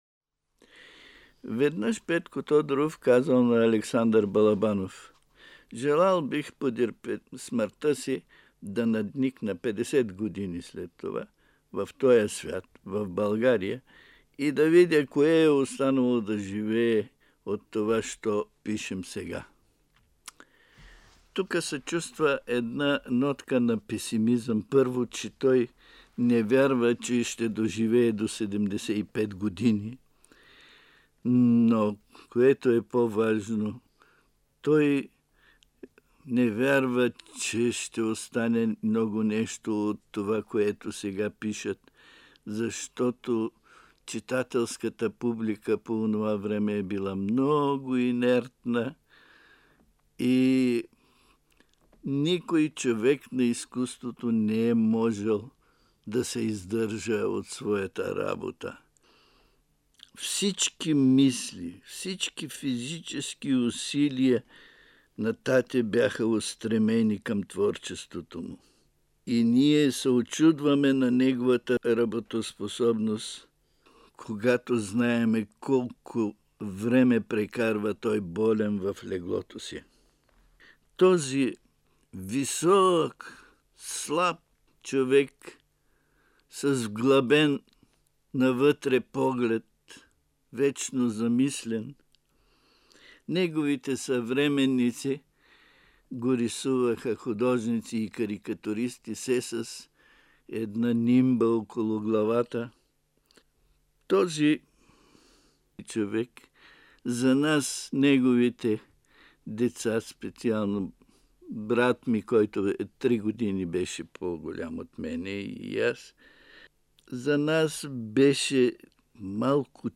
Запис 1984 година, Златен фонд на БНР: